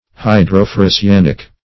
Search Result for " hydroferrocyanic" : The Collaborative International Dictionary of English v.0.48: Hydroferrocyanic \Hy`dro*fer`ro*cy*an"ic\, a. [Hydro-, 2 + ferrocyanic.]